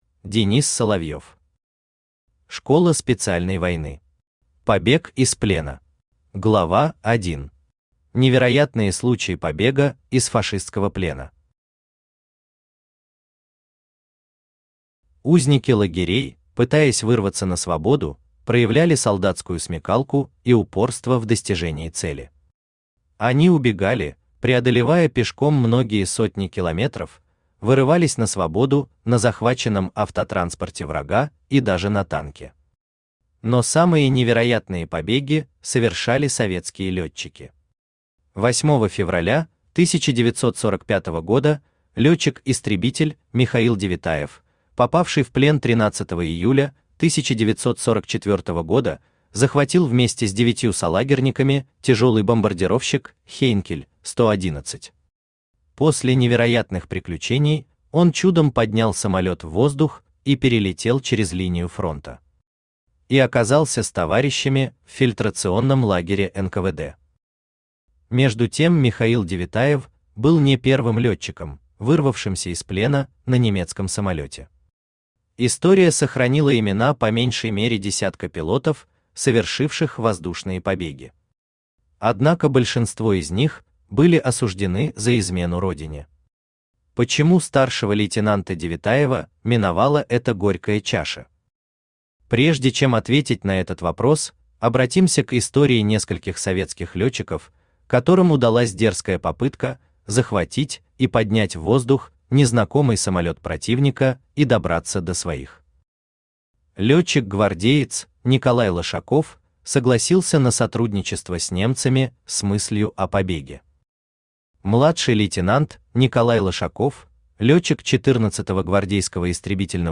Аудиокнига Школа специальной войны. Побег из плена | Библиотека аудиокниг
Побег из плена Автор Денис Соловьев Читает аудиокнигу Авточтец ЛитРес.